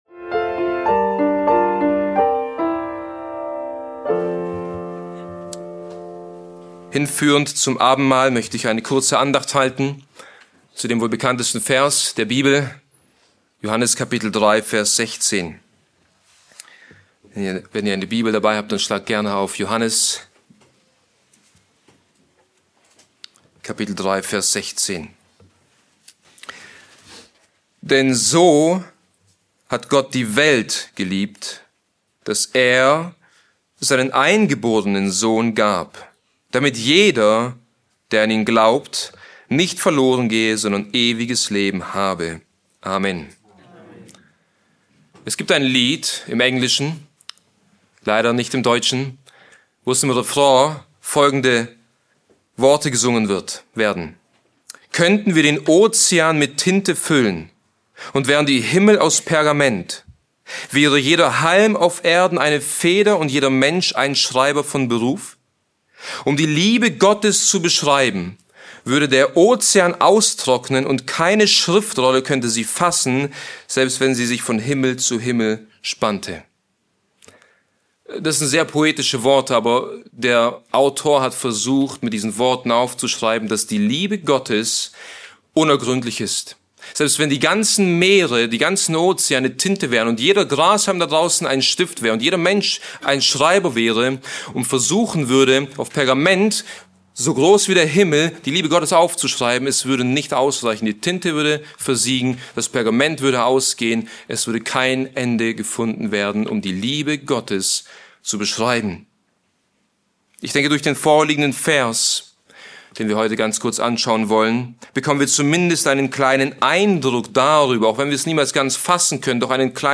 Beschreibung vor 2 Monaten Die Predigt behandelt die unergründliche Liebe Gottes anhand von drei Aspekten: **Der Ausdruck der Liebe** zeigt sich darin, dass Gott das Wertvollste gab – seinen einzigen, innig geliebten Sohn.